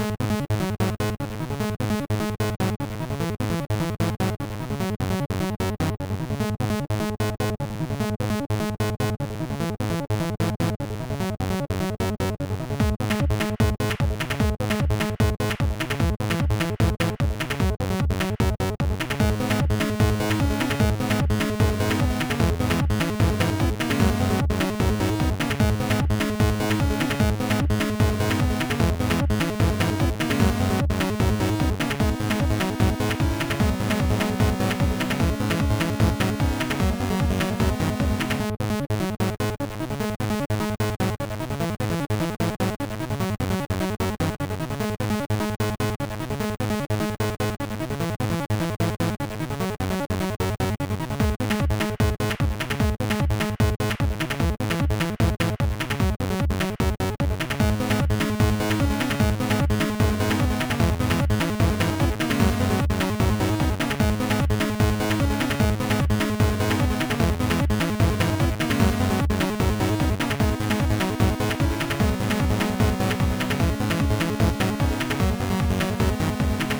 A Small work in Progress Chiptune